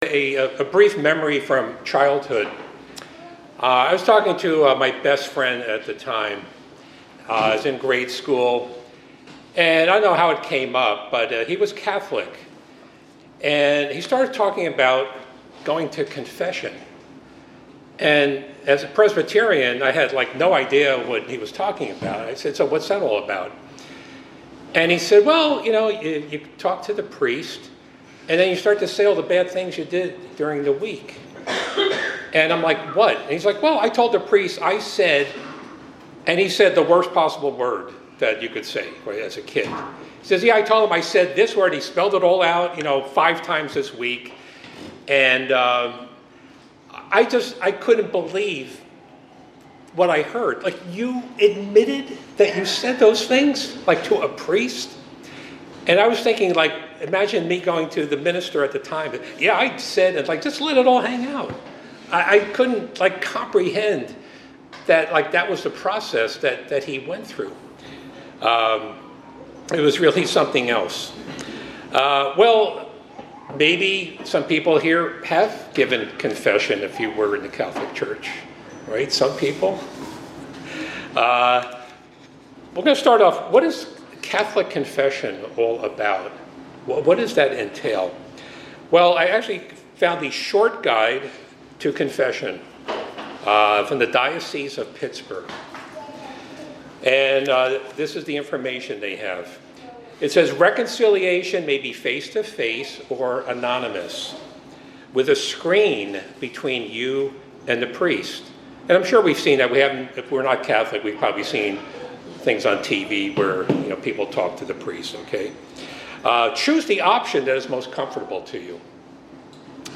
This message discusses the concept of confession within the context of Christianity, particularly contrasting Catholic practices with Biblical Truth. The speaker shares a personal anecdote from childhood about a friend's confession experience in the Catholic Church. It examines biblical passages to support the idea that confession should be directed to God rather than through a priest.